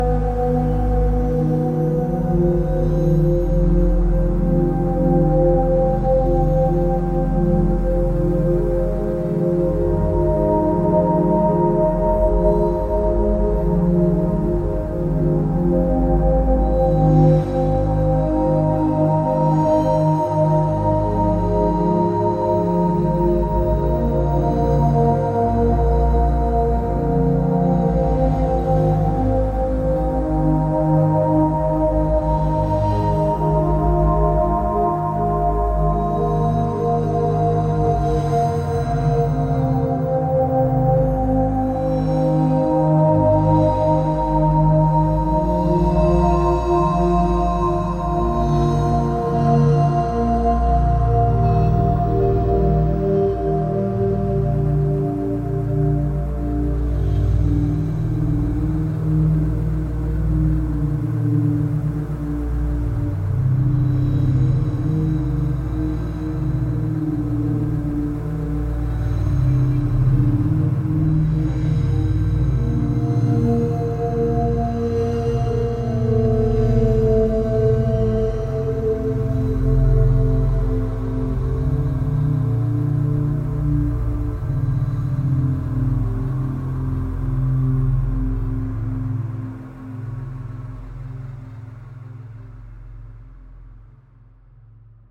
AI music created in the MusicKraze iPhone app
Instrumental: yes
Tags: ambient, electronic, instrumental